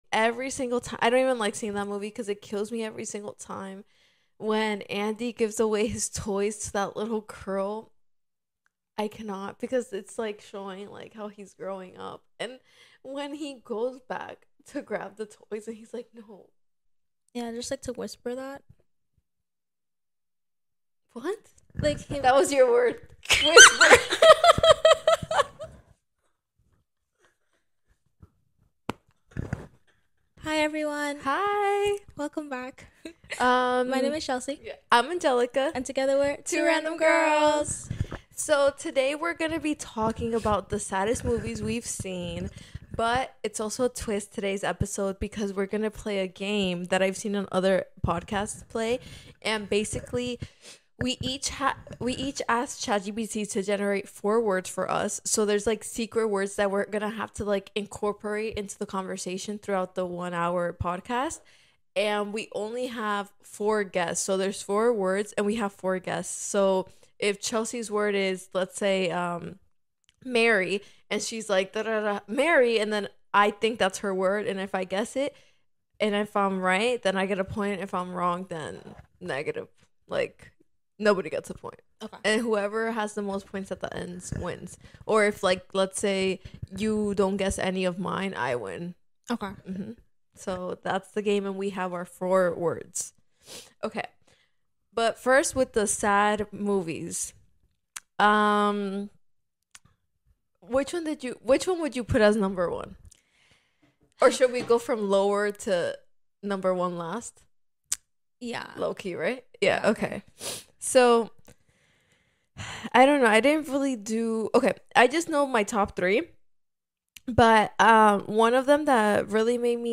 Genres: Comedy , Stand-Up